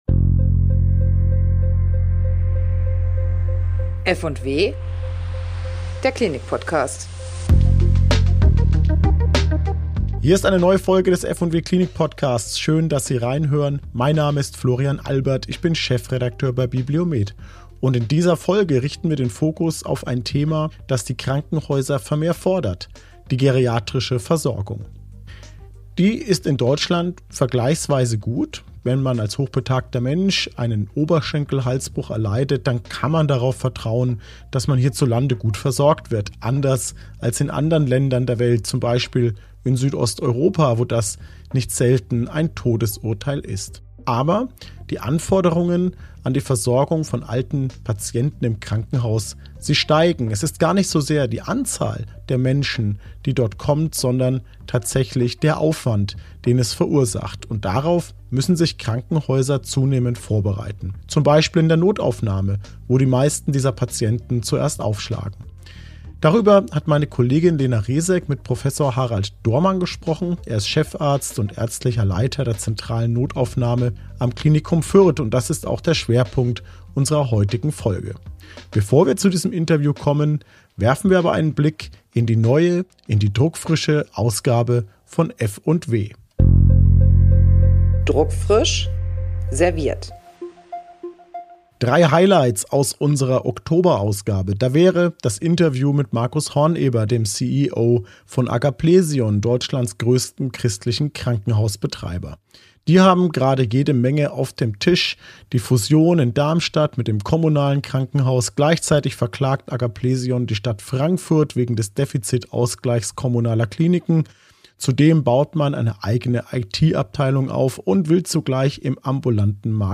Im Doppel-Interview